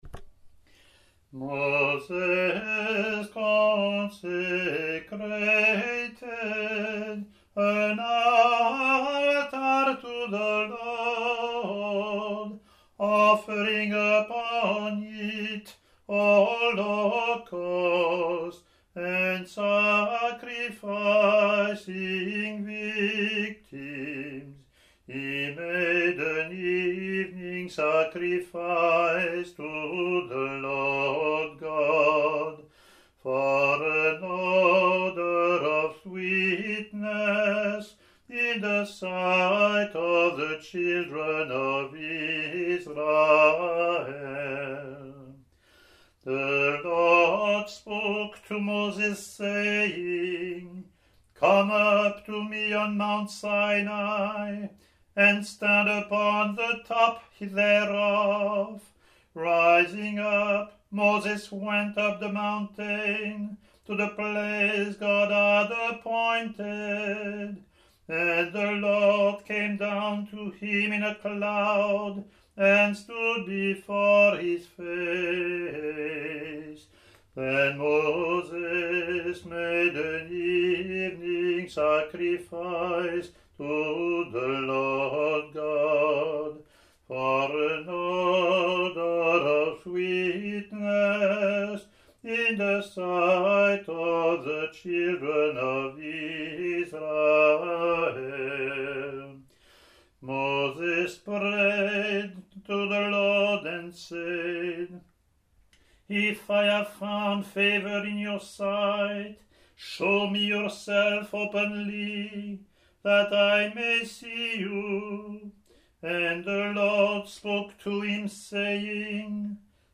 English antiphon – English verse
ot24-offertory-eng-pw.mp3